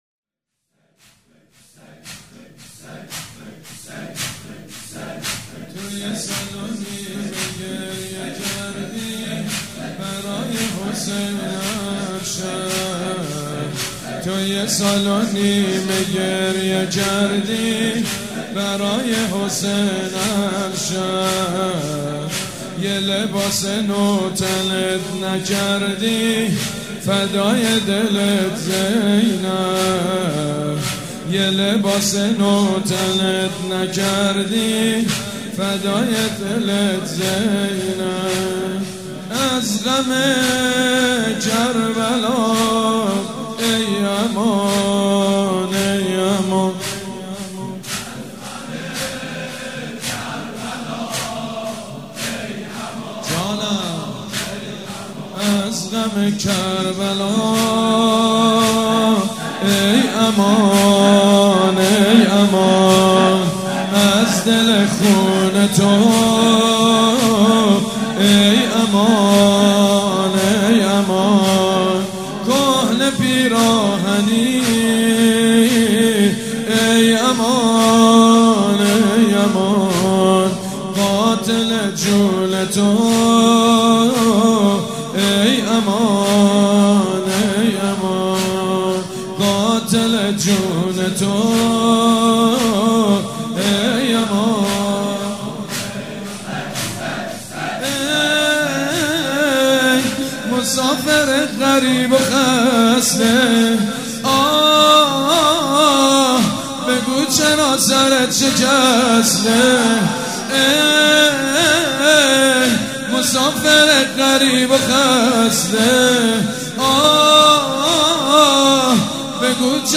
مراسم شهادت حضرت زینب کبری(سلام الله عليها) چهارشنبه ٢٣فروردین١٣٩٦ مجتمع فرهنگی مذهبی ریحانة الحسین(س)
سبک اثــر زمینه
زمینه.mp3